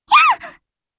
combobreak.wav